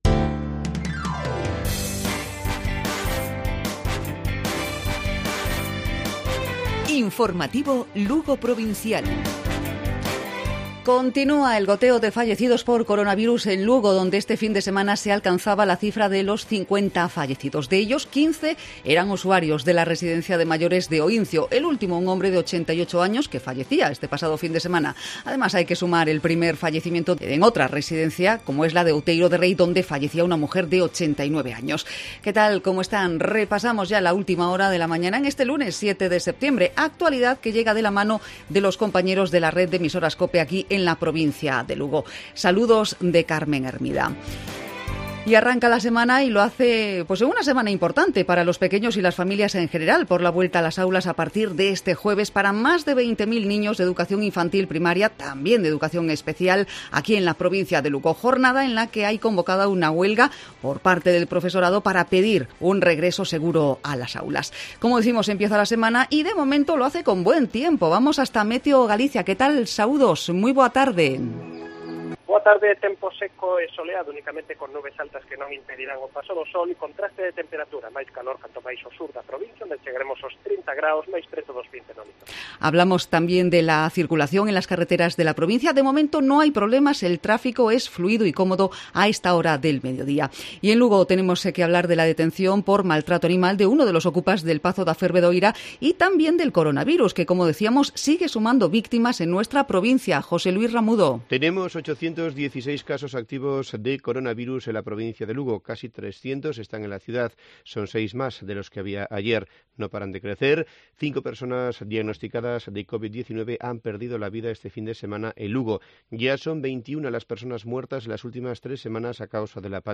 Informativo Provincial Cope Lugo. Lunes, 7 de septiembre 12:50-13:00 horas